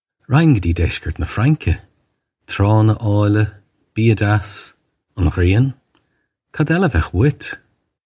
Pronunciation for how to say
Rakh-hin guh jee jesh-kurt nuuh Franka. Traw-unna awl-yuh, bee-a jass, on ghree-un - kod ella uh vay-ukh oo-it?
This is an approximate phonetic pronunciation of the phrase.